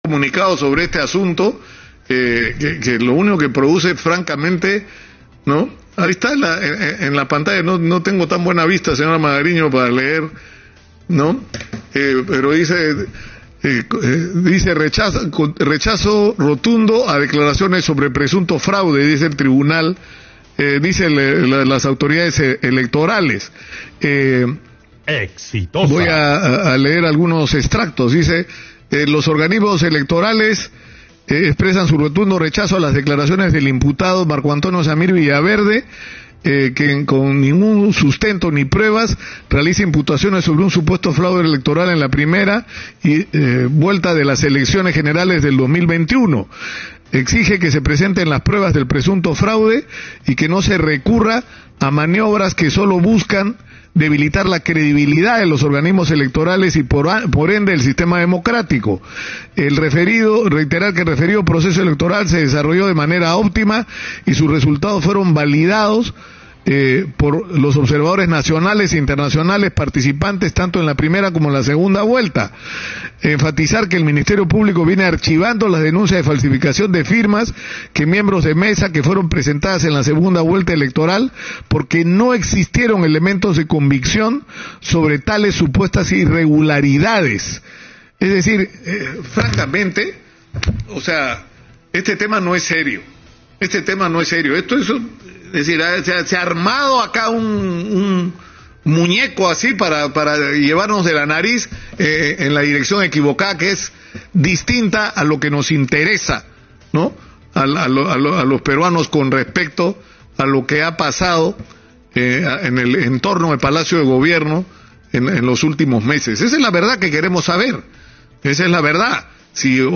El periodista Nicolás Lúcar, indicó que los organismos electorales del JNE y la ONPE rechazan las declaraciones de Zamir Villaverde, quien dijo que hubo fraude en la primera vuelta de las elecciones general del 2021.